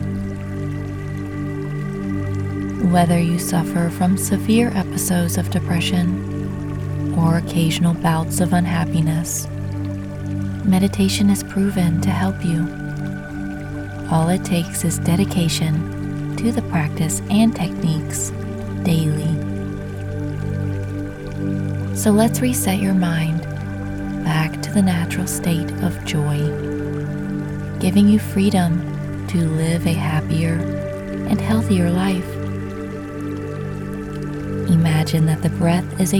The Mind Series Beat Depression Audio is designed to relax & speak to your subconscious through powerful hypnotic suggestions to help the listener shift to a more positive state of mind and work to become freed from the grip of depression.